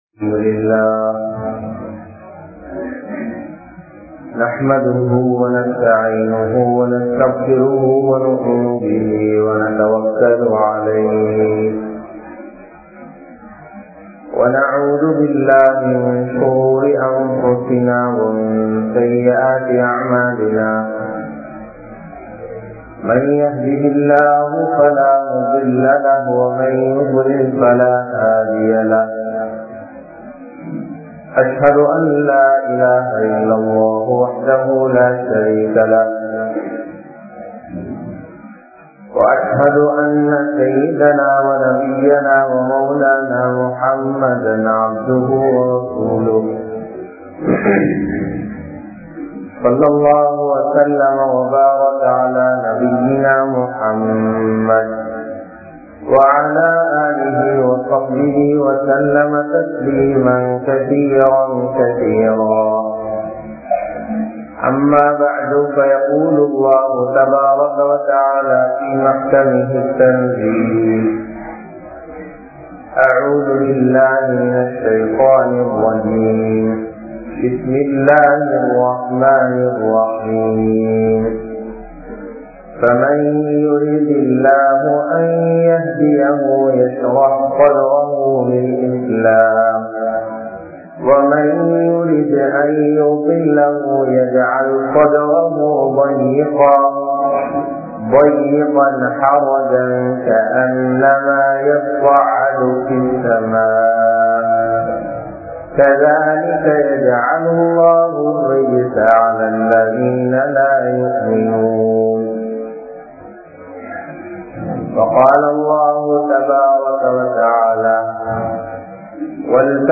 Dhauwath & Ahlaaq (தஃவத் & அஹ்லாக்) | Audio Bayans | All Ceylon Muslim Youth Community | Addalaichenai
Galle, Kaluwella, Meeran Jumua Masjidh